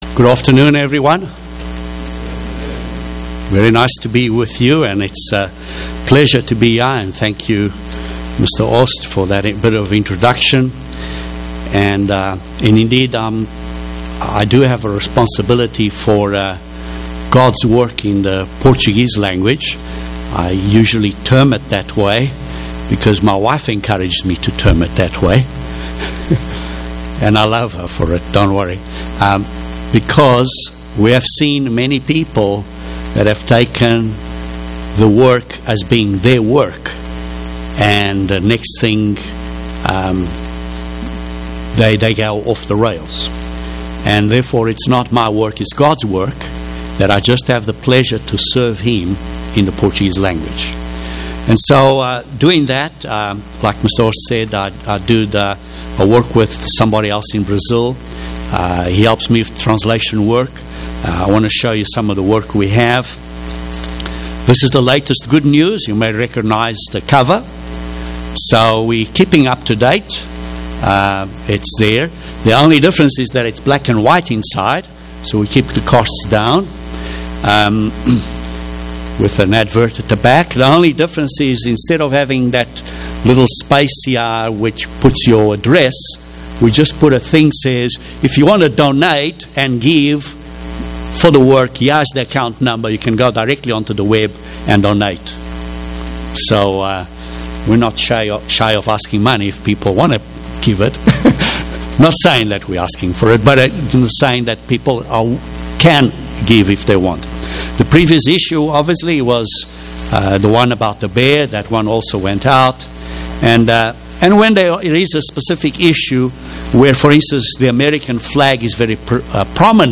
It is preceded by some opening remarks about the Portuguese work and pertinent commentary about recent world events that lead into the sermon message.
UCG Sermon Transcript This transcript was generated by AI and may contain errors.